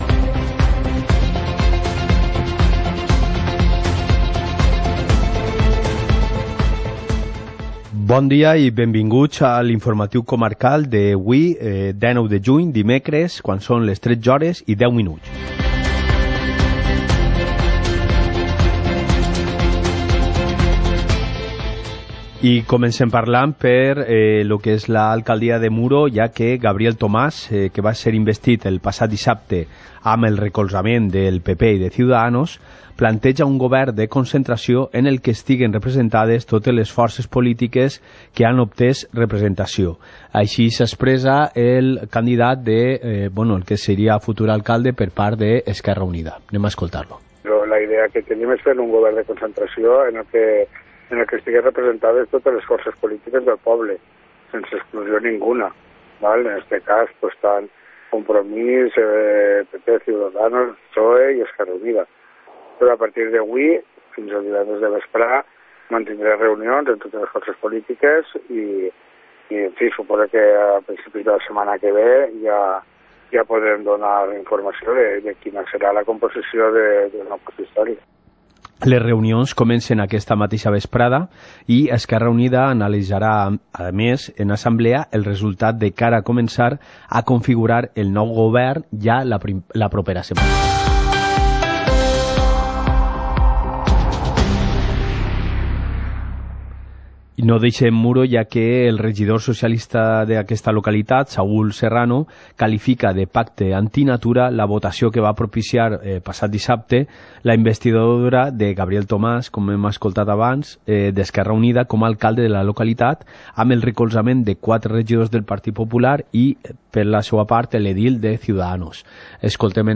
Informativo comarcal - miércoles, 19 de junio de 2019